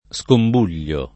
scombuio [ S komb 2L o ], -bui